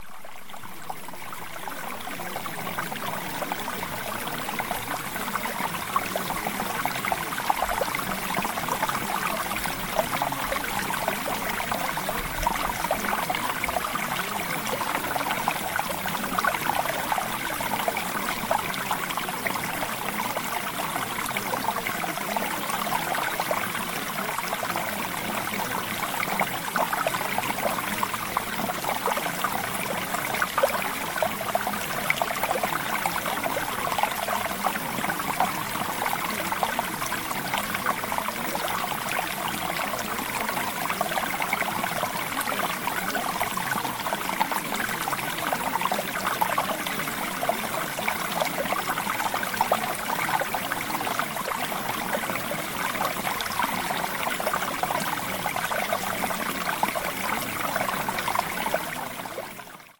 LANGUAGE LEARNING – Mountain Stream (Sample)
LANGUAGE-LEARNING-Mountain-Stream-Sample.mp3